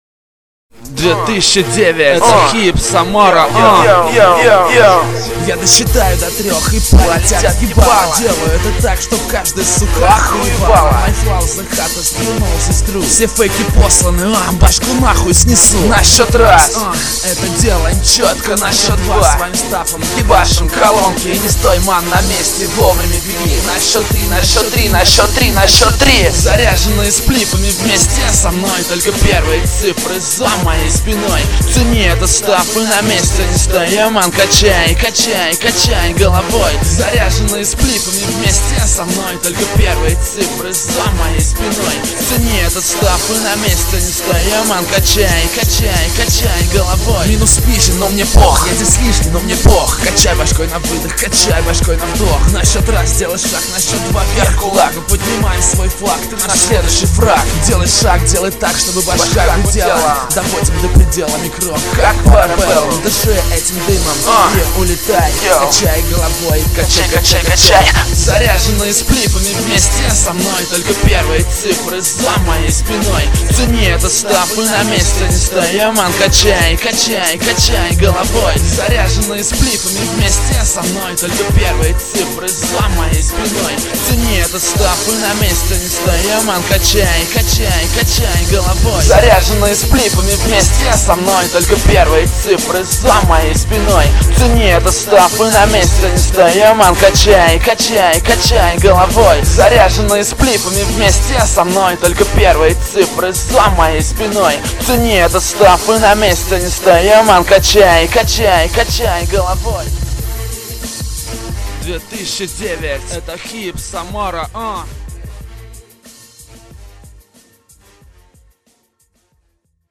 Музыкальный хостинг: /Рэп